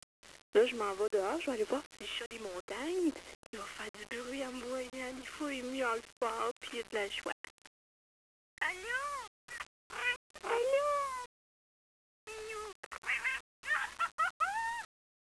miaoupoupou.wav